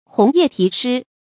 紅葉題詩 注音： ㄏㄨㄥˊ ㄧㄜˋ ㄊㄧˊ ㄕㄧ 讀音讀法： 意思解釋： 唐代宮女良緣巧合的故事。